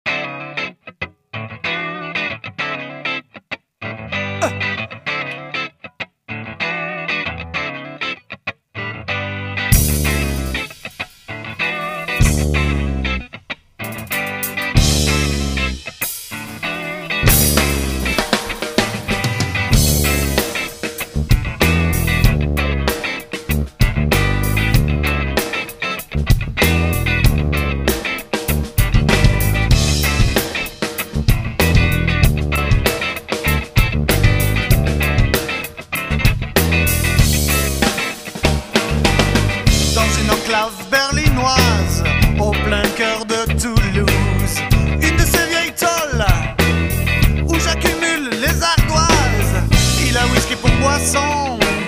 basse fretless
Batterie